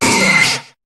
Cri de Scarabrute dans Pokémon HOME.